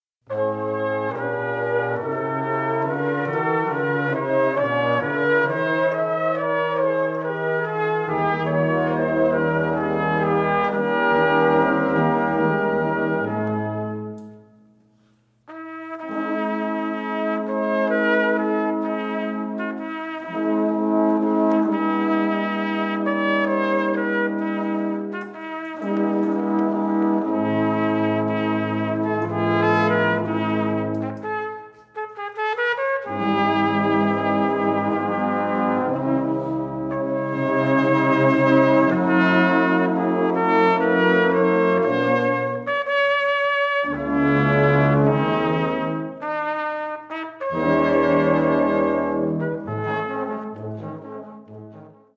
stereo
b flat cornet solo
e flat cornet solo
Performed on period instruments and mouthpieces (c1855-1872)